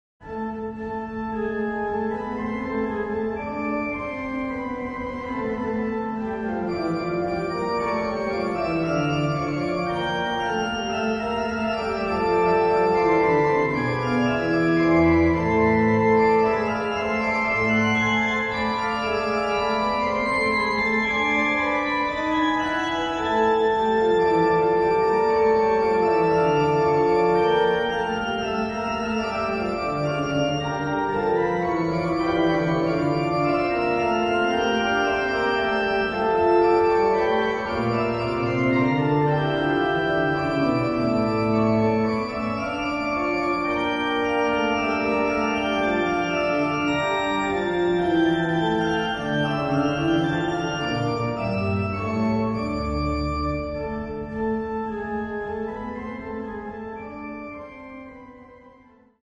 Organo "Amedeo Ramasco", Chiesa Parrocchiale di Crocemosso
Organo